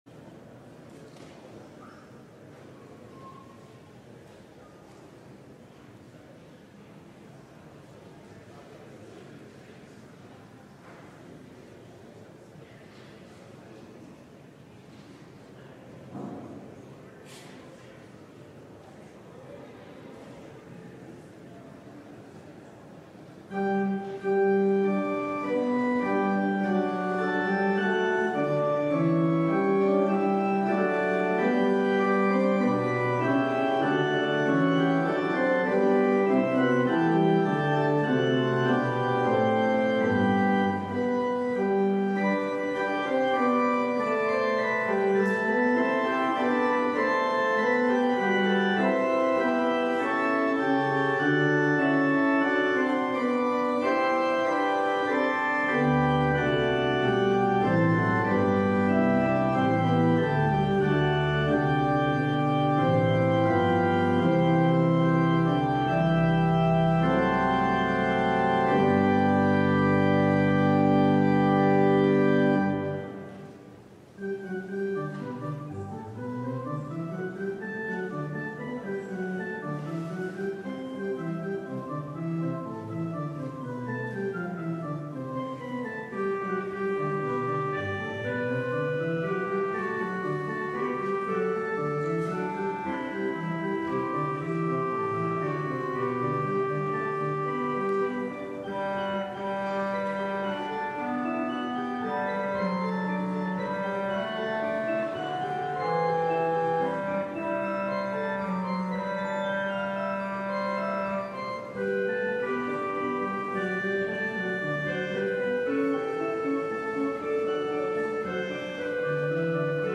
LIVE Midday Worship Service - The Imperfect Anointed: Tamar, Amnon And Absalom